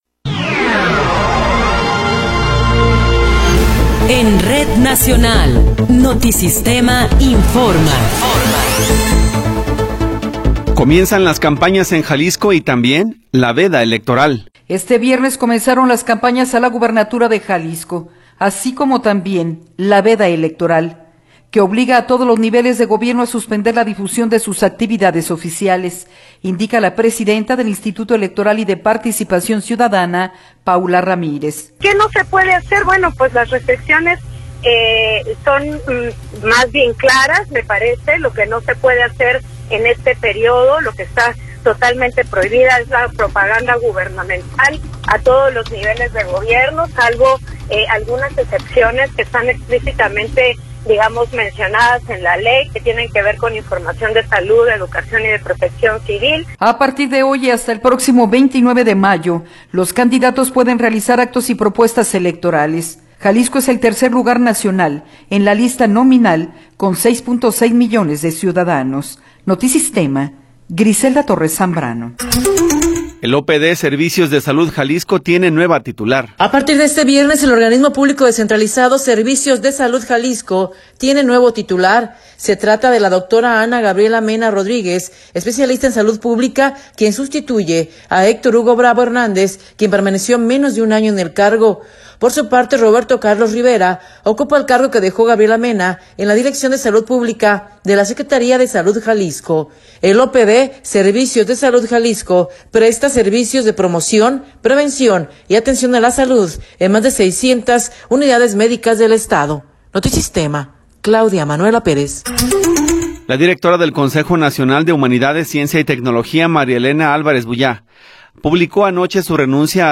Noticiero 10 hrs. – 1 de Marzo de 2024
Resumen informativo Notisistema, la mejor y más completa información cada hora en la hora.